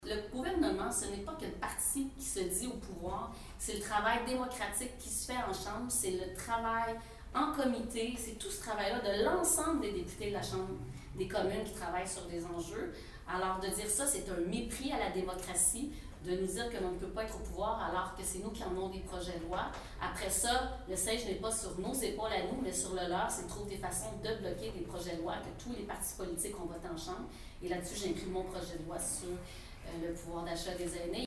La députée sortante de Shefford a tenu une conférence de presse vendredi à Granby en compagnie de plusieurs membres de son équipe et de bénévoles.
Andréanne Larouche a d’ailleurs répondu aux critiques libérales concernant l’impertinence de voter pour le Bloc Québécois :